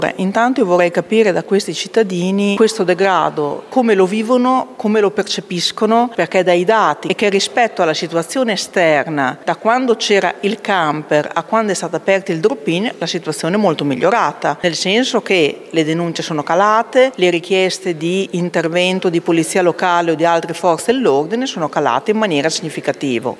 La risposta dell’assessore alle politiche sociali Francesca Maletti: